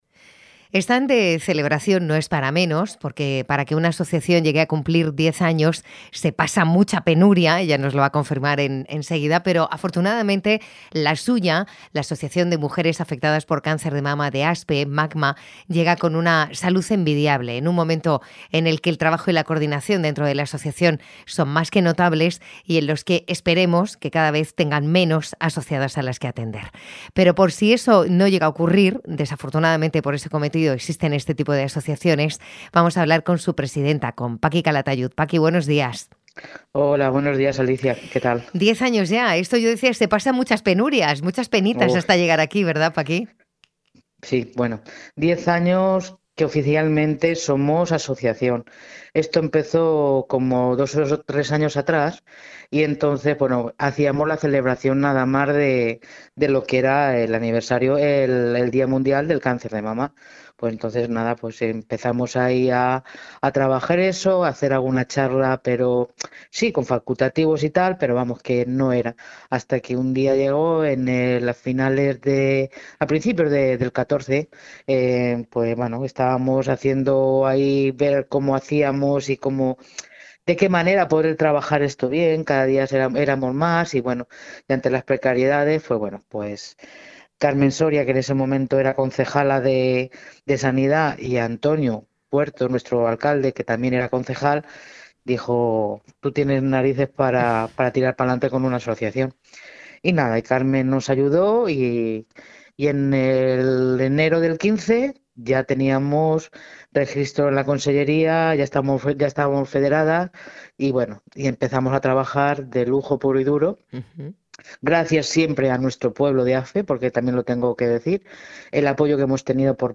Les dejamos con la entrevista que mantuvimos